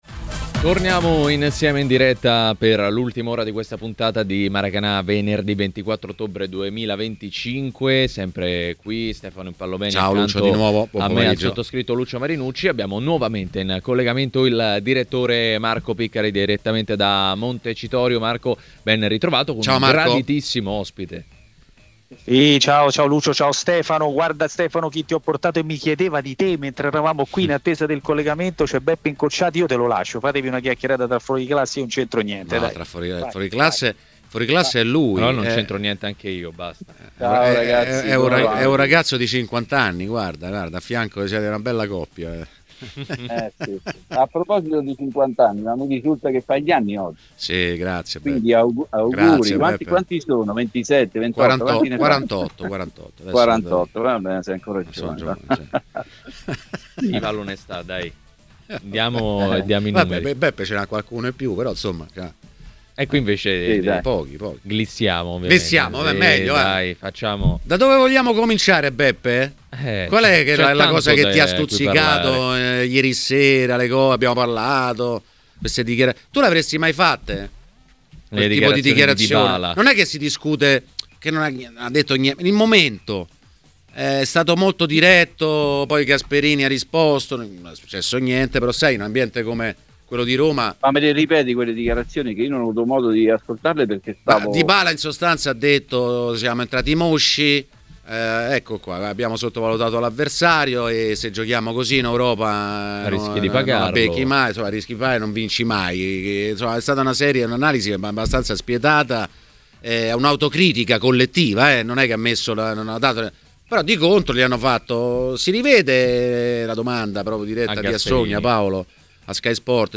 Ospite di Maracanà, nel pomeriggio di TMW Radio, è stato l'ex calciatore e tecnico Giuseppe Incocciati: "Le parole di Dybala?